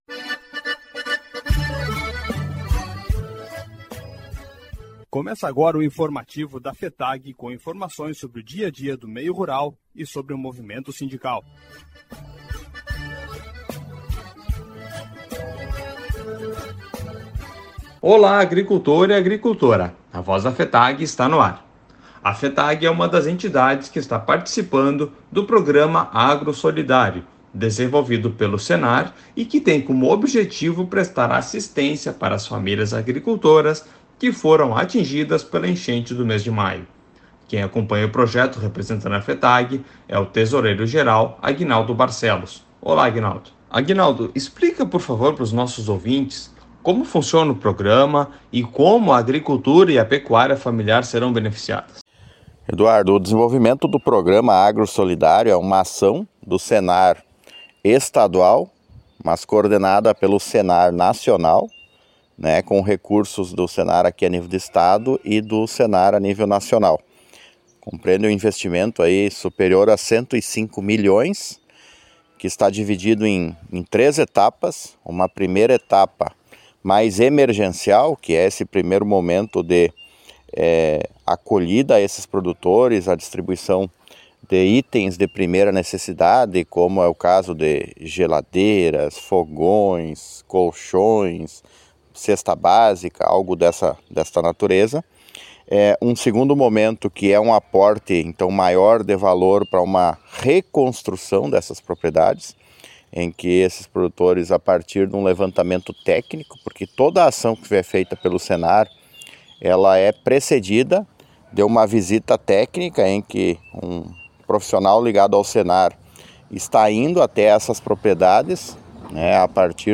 Programa de Rádio A Voz da FETAG-RS